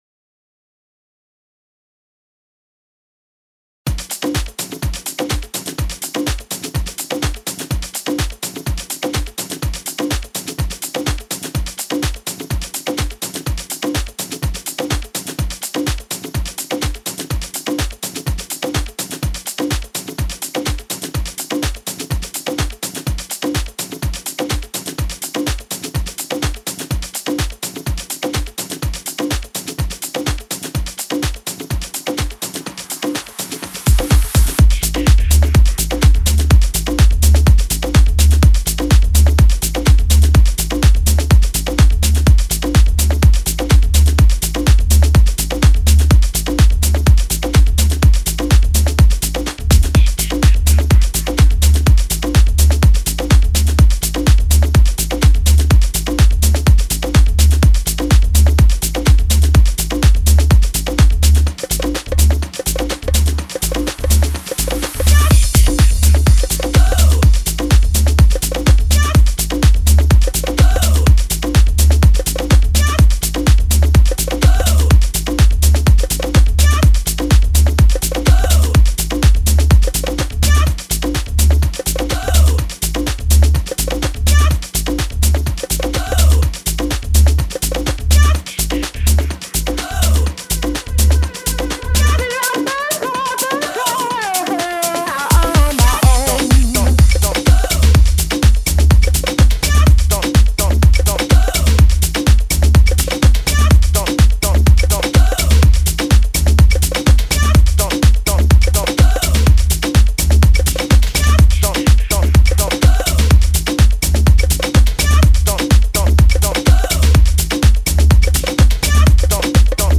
GENRE : House
BPM : 125 KEY : Dm